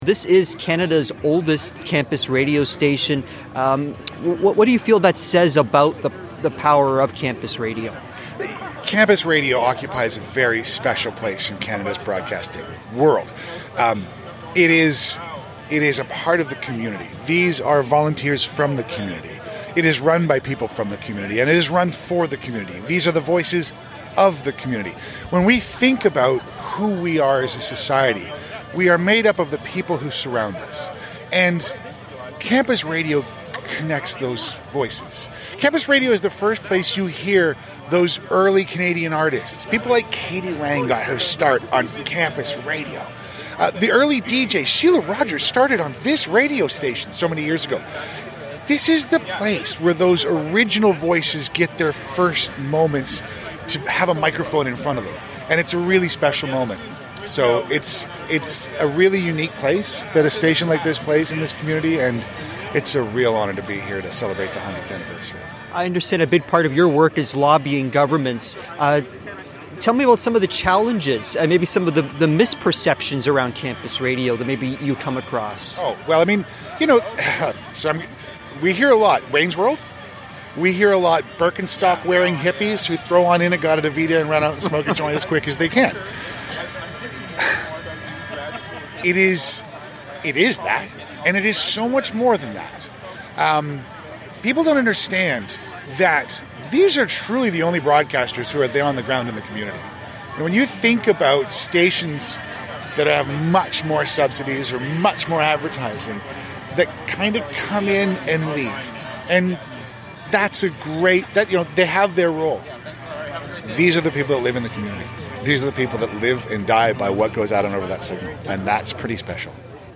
at CFRC's centennial plaque unveiling ceremony